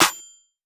Hustle Squad snare (17).wav